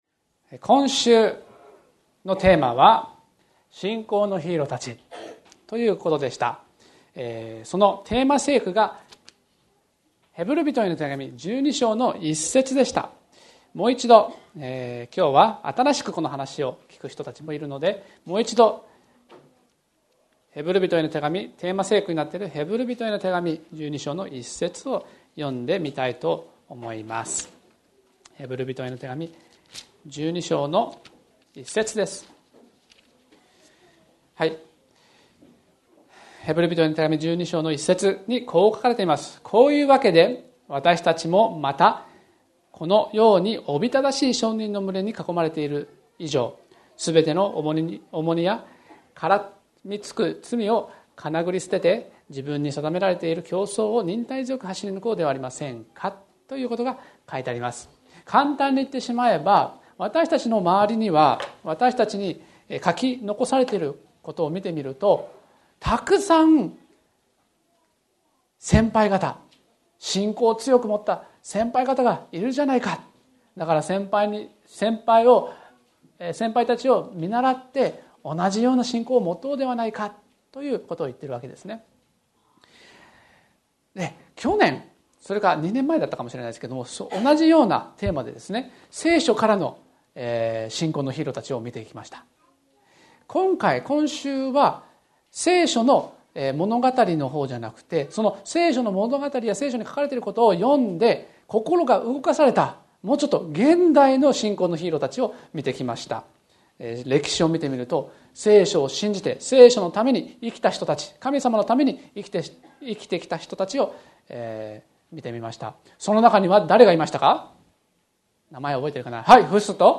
礼拝説教